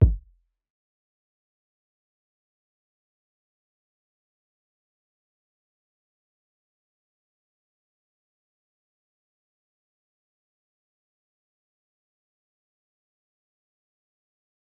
Kicks
DMV3_Kick 5.wav